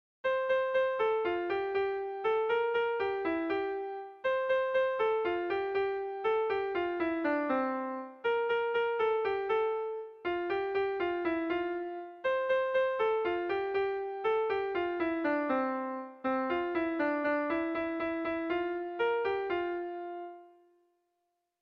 Bertso melodies - View details   To know more about this section
Irrizkoa
A1A2BD..